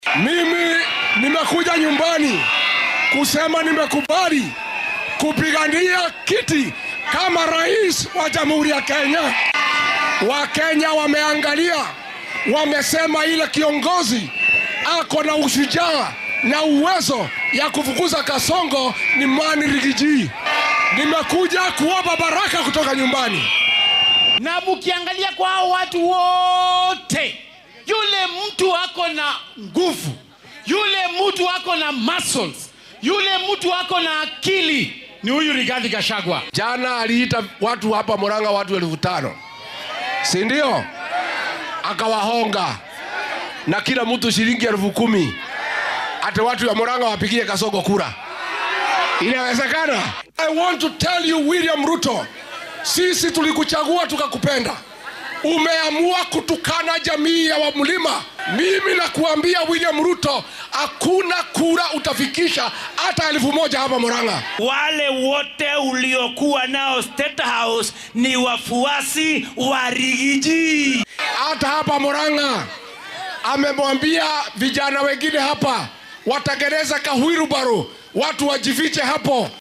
Madaxa xisbiga DCP ayaa ka hadlay xilli uu hogaaminayay mas’uuliyiin ka tirsan xisbigiisa iyo madax kale oo socdaal ku tagay gobolka Mt. Kenya, halkaasoo uu u adeegsaday jidgooyo uu ku sheegay inuu u tartamayo xilka madaxweynaha.